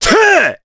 Gau-Vox_Attack2_jp.wav